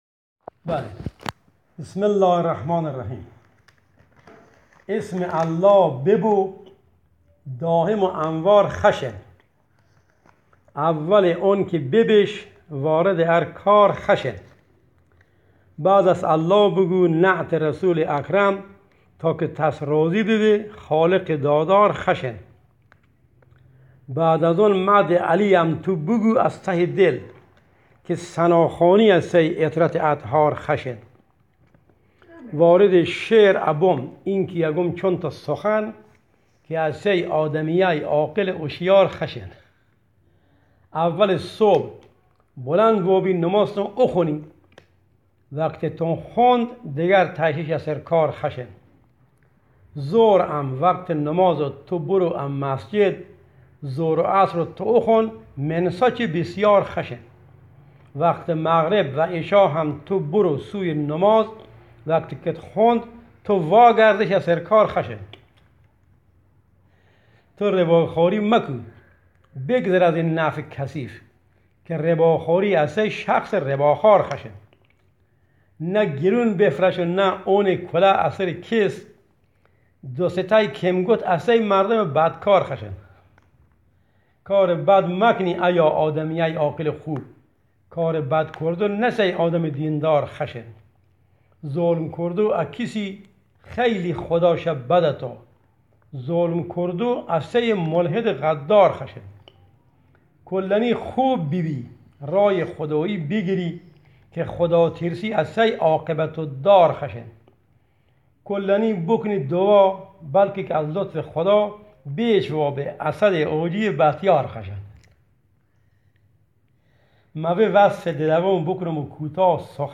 شعر